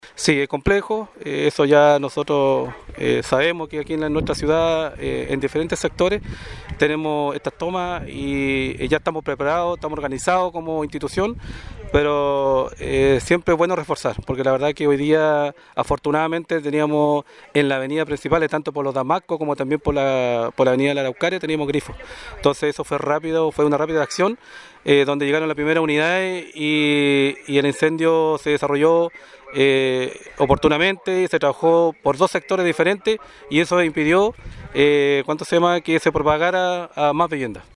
El Segundo Comandante reconoció que resulta extremadamente dificultoso combatir incendios en los campamentos de la ciudad, donde generalmente se presentan calles bloqueadas por vehículos, escasez de grifos y la rápida propagación de las llamas en estructuras de material liviano.